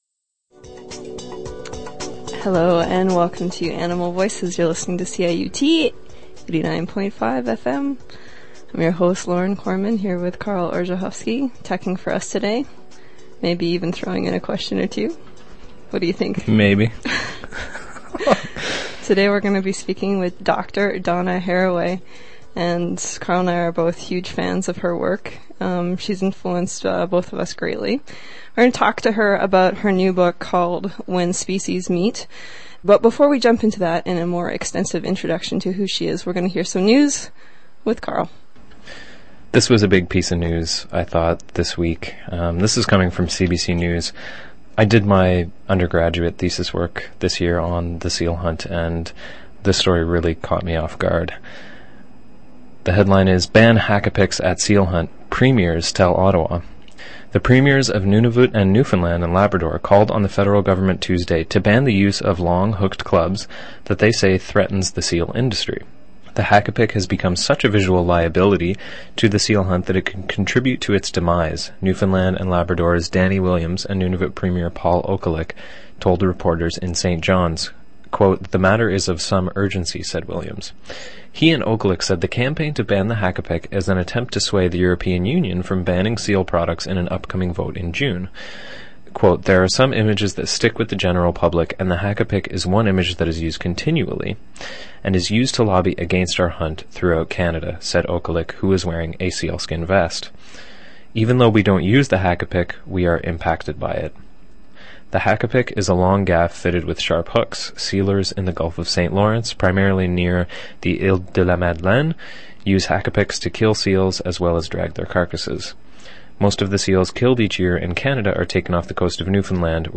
When Species Meet: An Interview with Donna Haraway
In this interview we speak with Dr. Donna Haraway about her new book,When Species Meet.